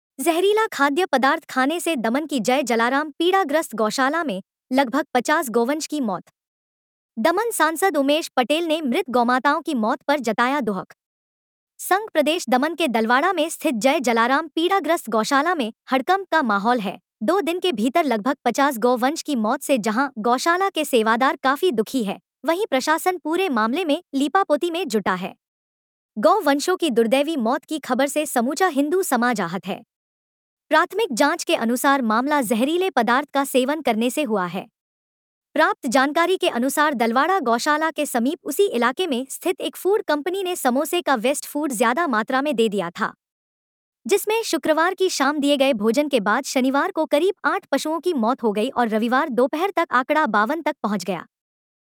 दमन के सांसद उमेश पटेल ने पूरे मामले में दी प्रतिक्रिया…..